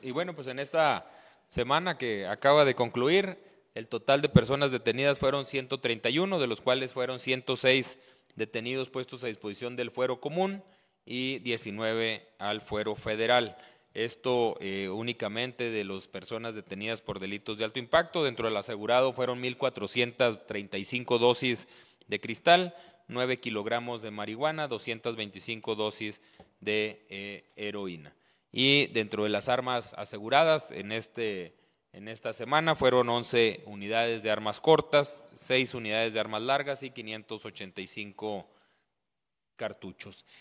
Los operativos conjuntos en el Estado de Chihuahua, en los que participan elementos de los tres órdenes de gobierno, condujeron al decomiso de 1 mil 435 dosis de metanfetaminas durante el período del lunes 5 al lunes 19 de mayo, según el reporte que presentó esta mañana la Secretaría de Seguridad Pública del Estado (SSPE) en su conferencia de prensa semanal.